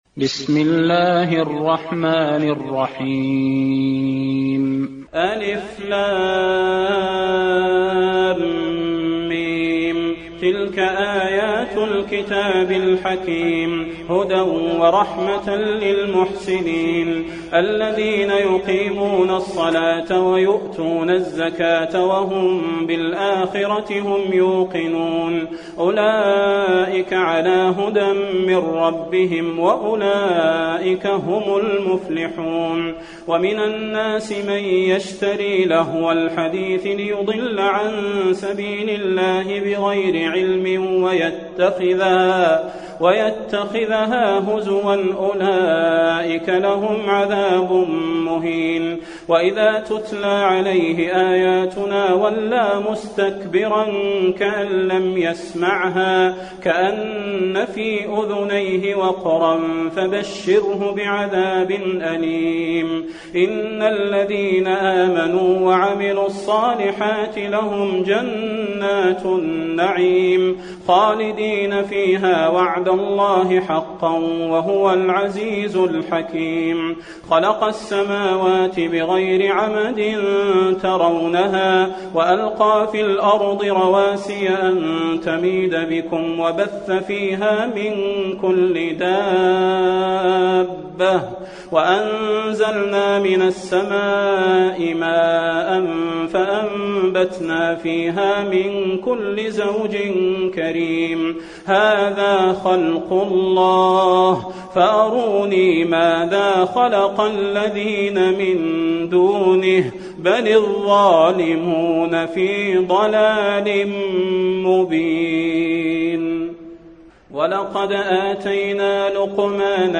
المكان: المسجد النبوي لقمان